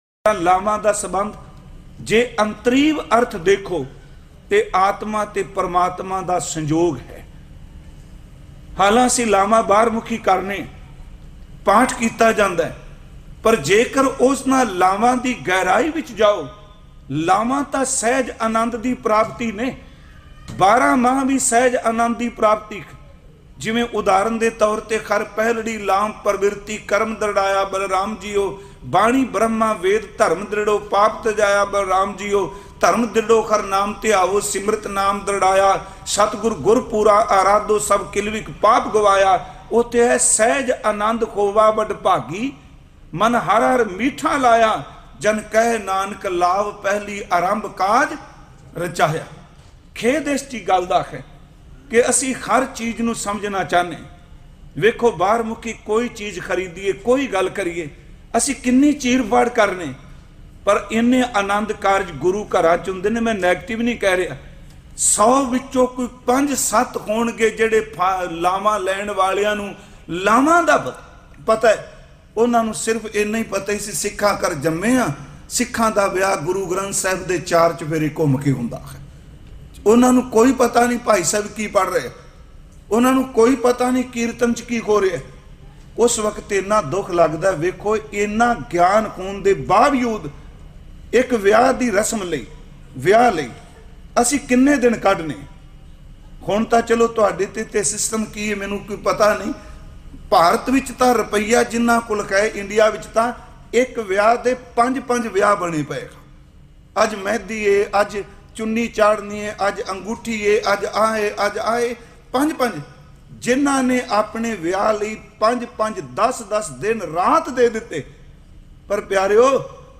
Chaar Laavan Basic Katha-2023
Chaar Laavan_Basic Katha-2023.mp3